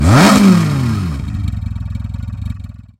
V8 Engine Rev
A powerful V8 engine revving aggressively with deep exhaust note and throttle blips
v8-engine-rev.mp3